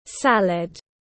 Món rau trộn tiếng anh gọi là salad, phiên âm tiếng anh đọc là /ˈsæl.əd/